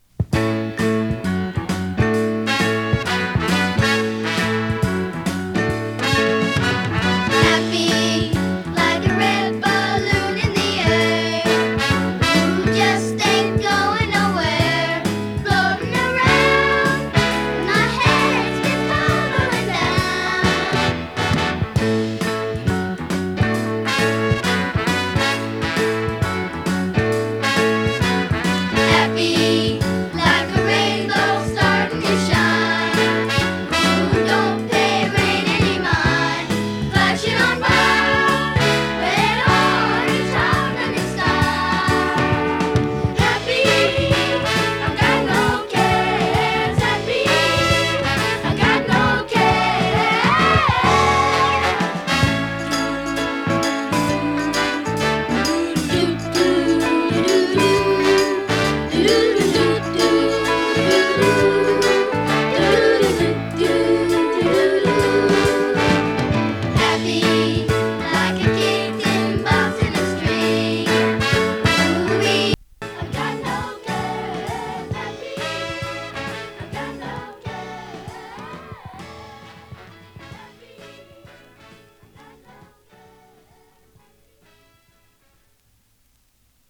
子供コーラスがキュートなソフトロック